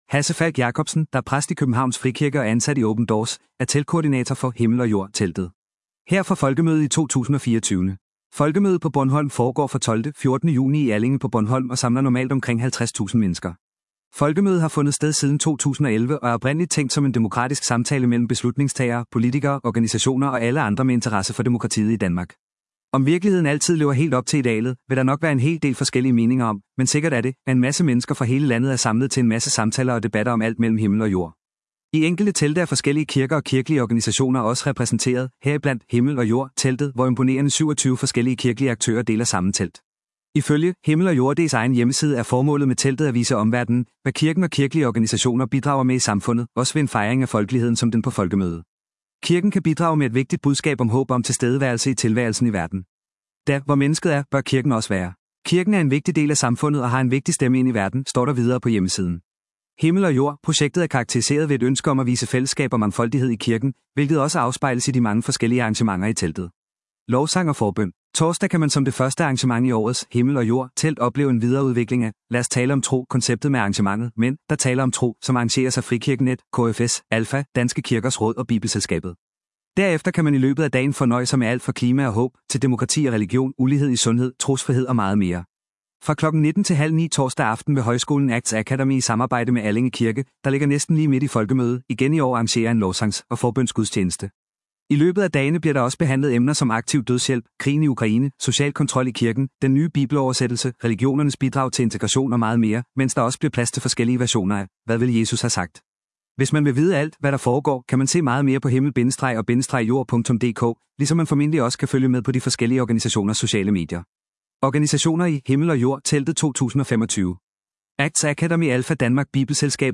Her fra Folkemødet i 2024.